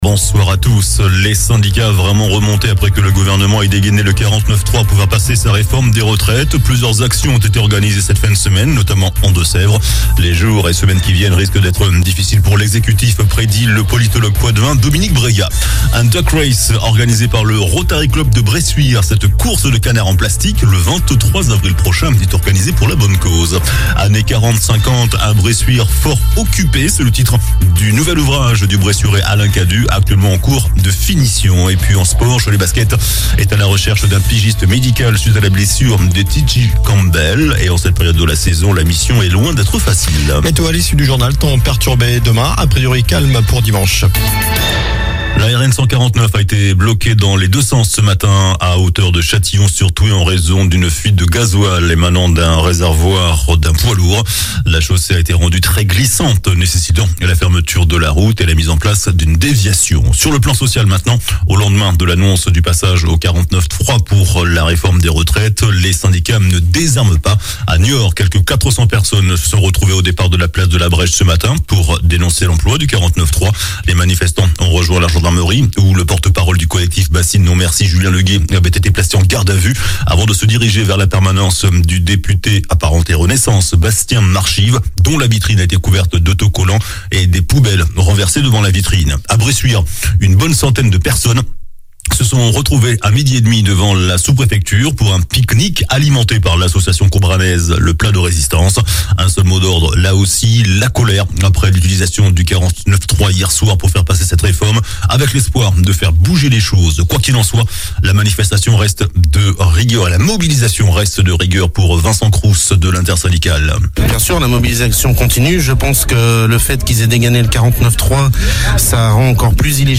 JOURNAL DU VENDREDI 17 MARS ( SOIR )